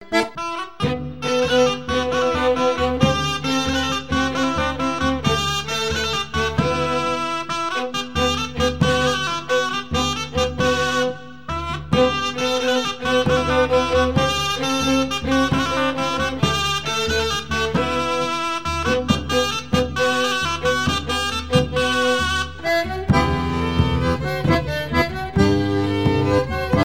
Région ou province Marais Breton Vendéen
danse : ronde : grand'danse
Pièce musicale éditée